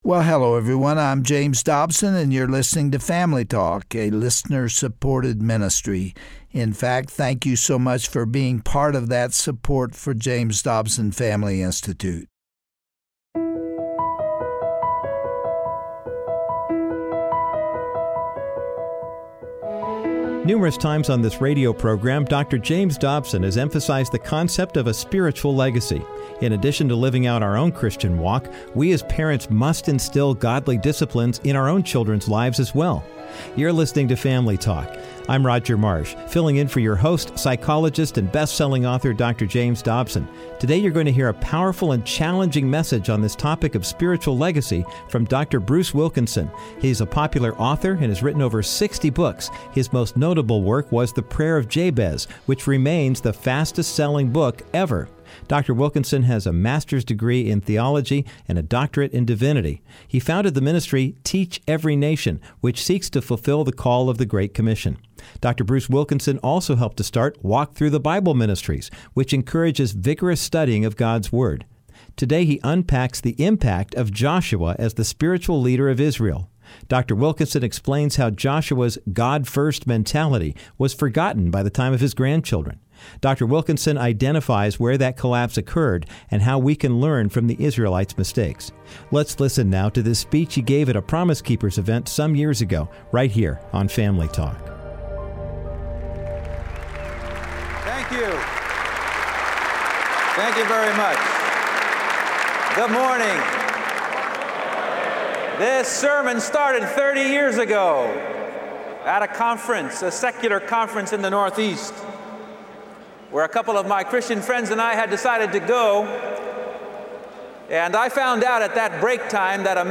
On today’s edition of Family Talk, Dr. Bruce Wilkinson delivers a passionate message on the importance of leaving a spiritual legacy. He urges parents not to allow compromise or apathy to seep into their own Christian walk.